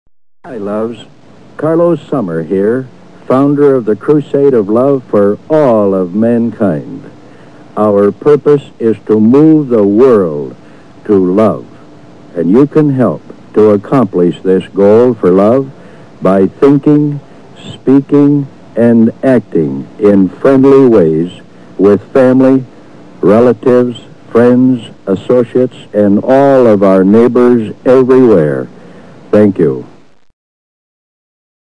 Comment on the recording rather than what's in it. This commercial ran for many years on Toledo television, starting anywhere from the early to mid-80s, often late at night, when the ad rates were low.